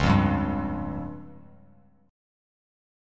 C#Mb5 chord